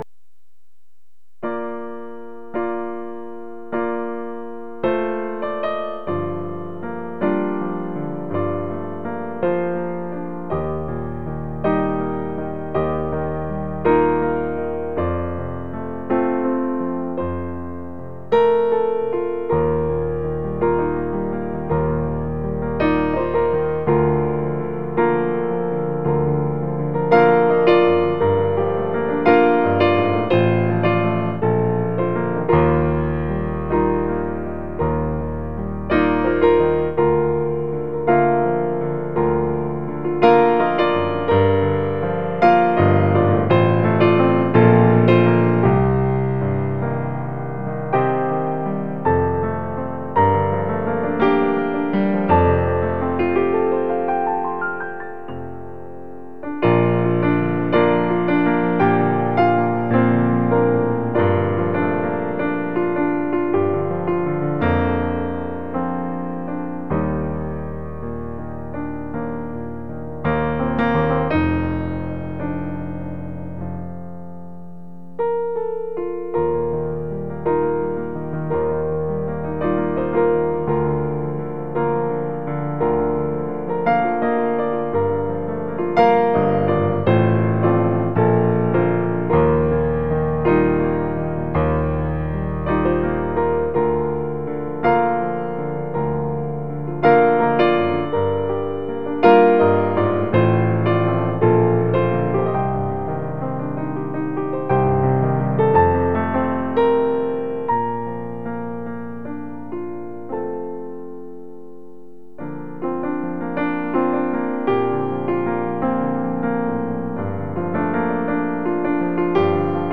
Acoustic Piano Cover